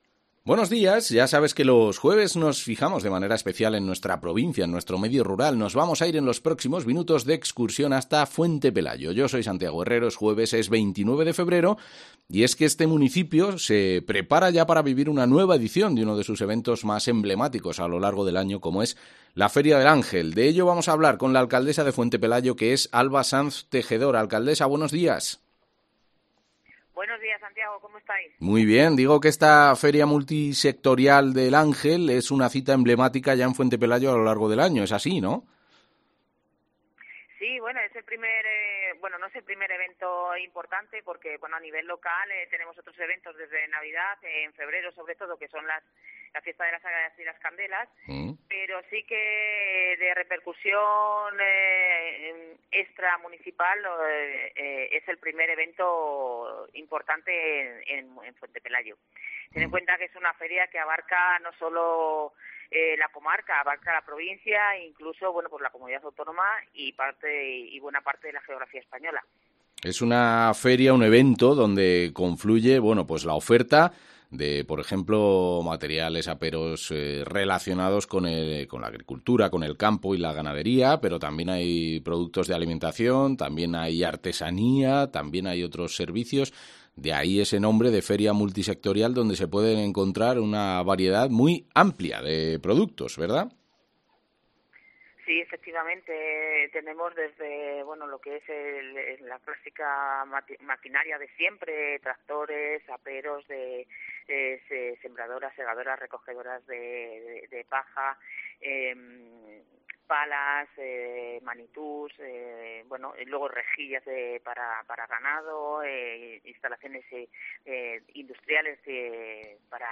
AUDIO: Es la alcaldesa de Fuentepelayo